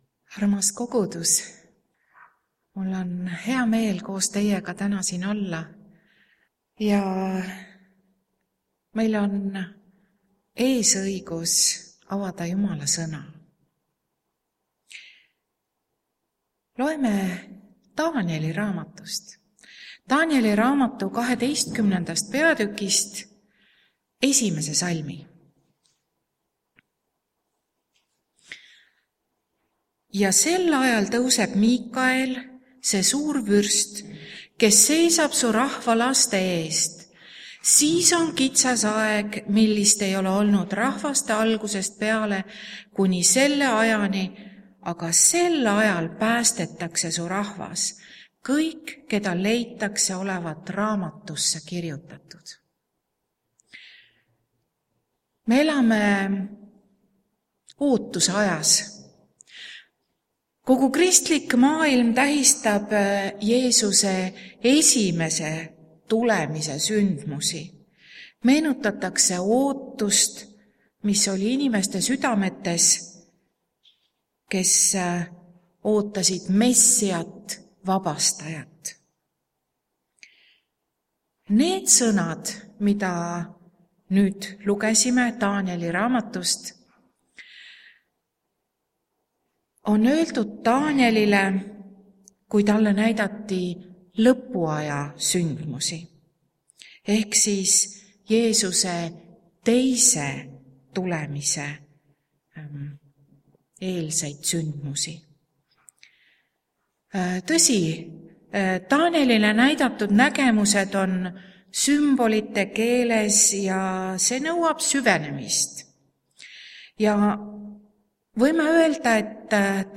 TAANIEL OTSIMAS HALASTUST Laulame koos ka ühe laulu...
Jutlused